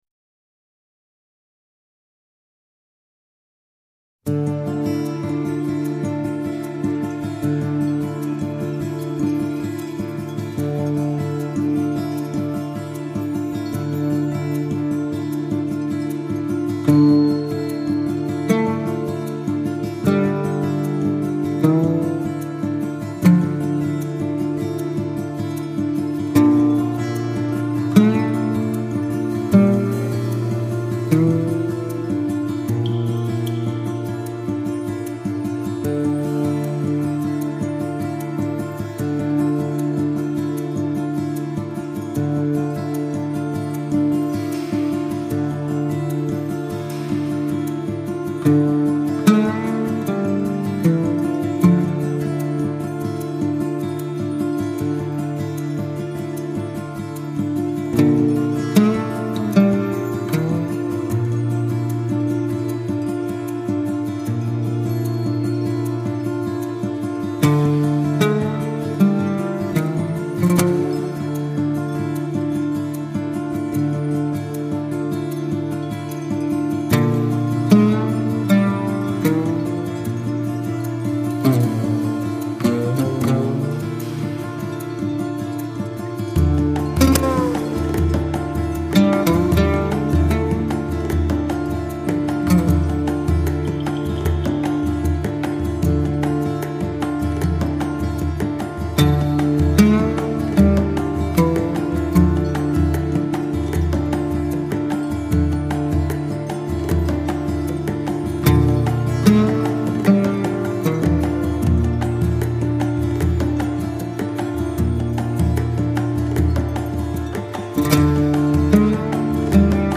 这就是为什么这种被指尖弹拨出来的声声乐音会散发出如此浓郁的异国情调的原因。